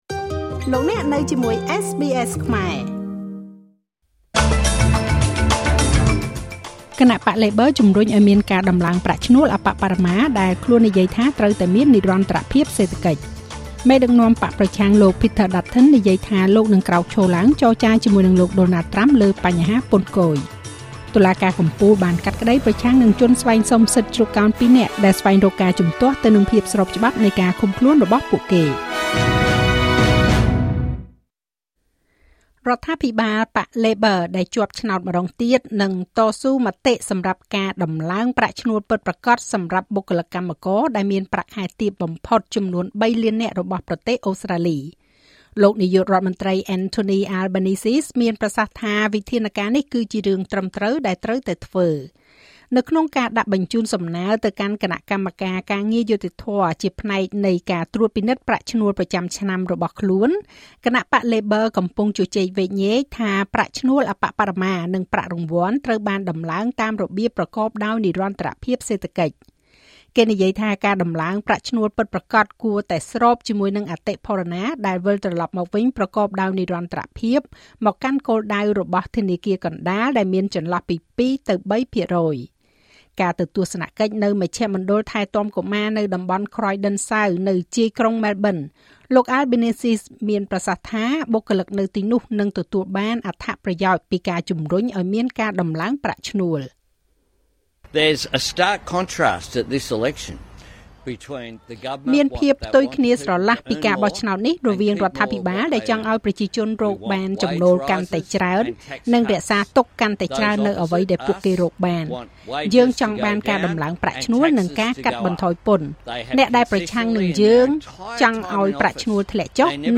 នាទីព័ត៌មានរបស់SBSខ្មែរ សម្រាប់ថ្ងៃពុធ ទី២ ខែមេសា ឆ្នាំ២០២៥